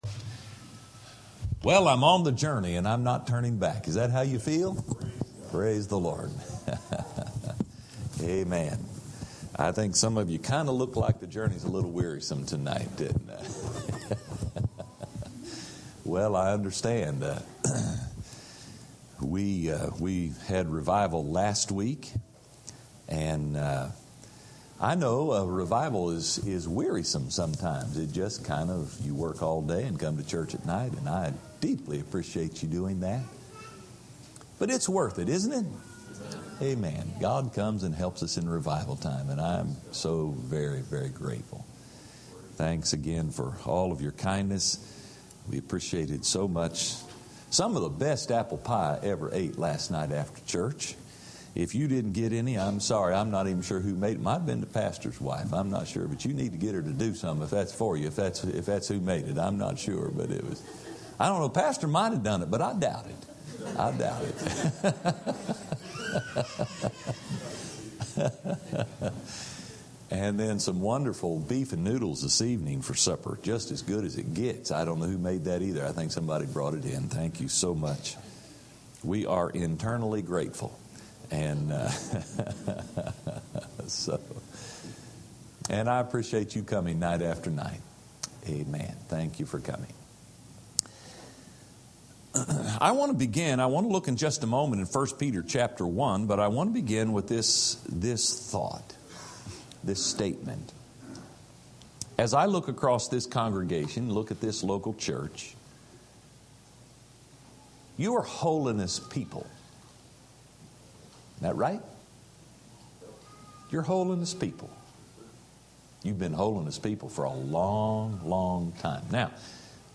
Series: Spring Revival 2016 Tagged with cleansing , consecration , death to self , holiness , holy